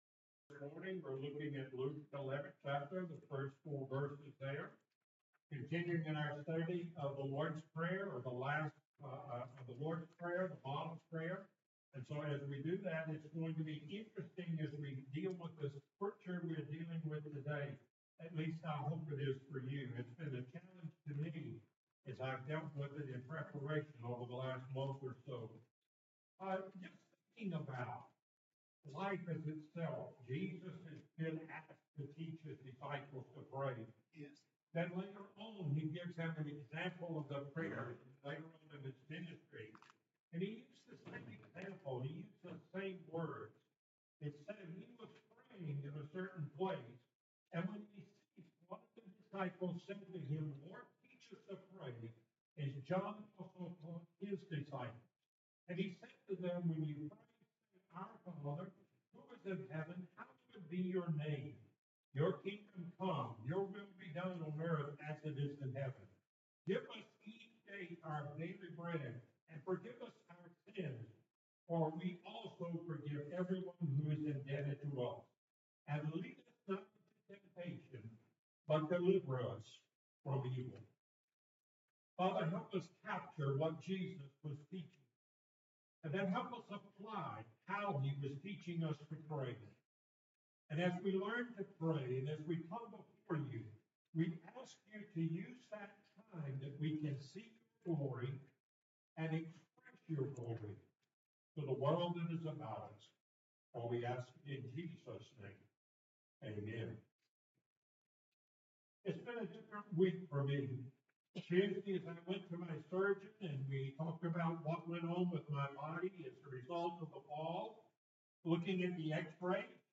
The Model Prayer Passage: Luke 11:1-4 Service Type: Sunday Morning Topics